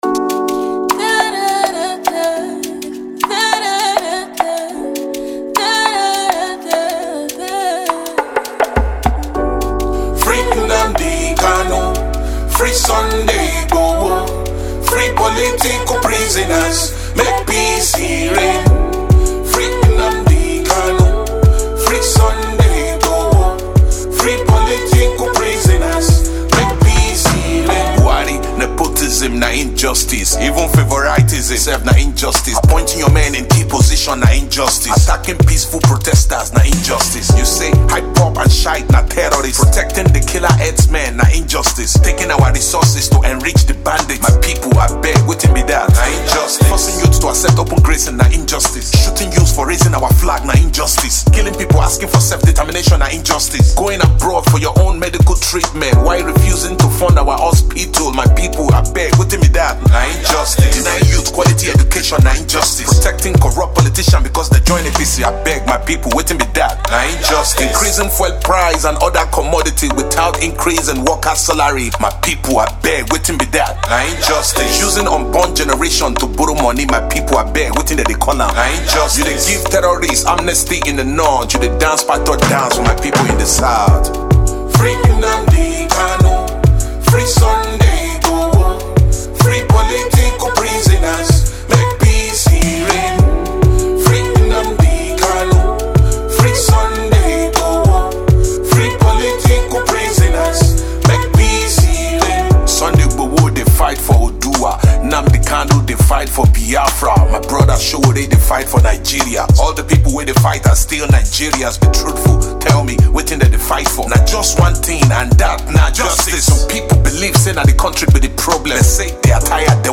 Veteran rapper/ singer